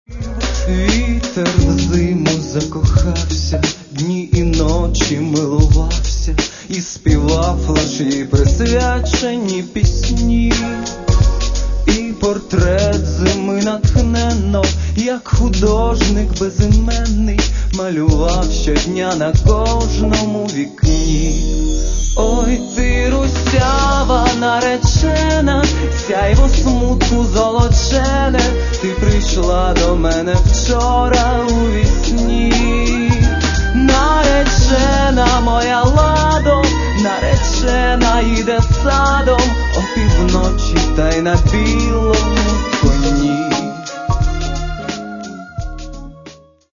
Каталог -> Поп (Легкая) -> Сборники
синт-поп и неоромантика